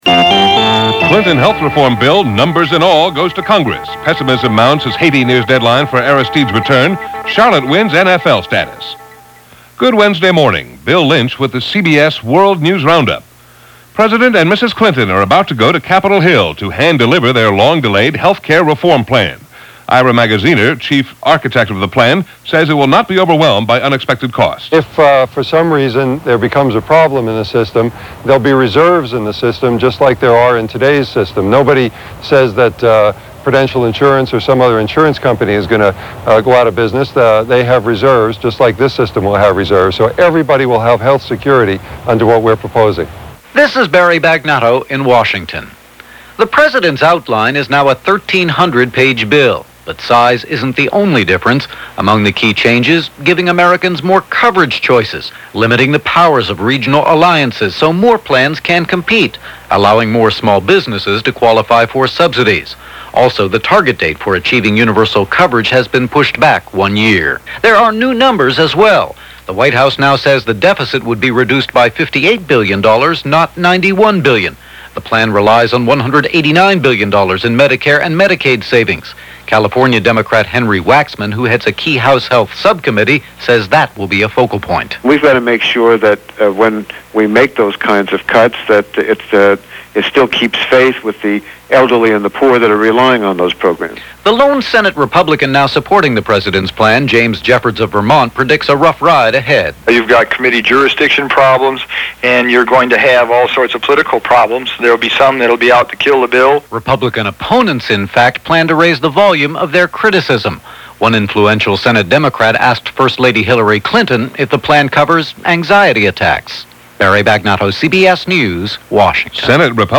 And along with the Clinton’s pitch on Healthcare, that’s just a little of what happened, this October 27th in 1993 as presented by the CBS World News Roundup.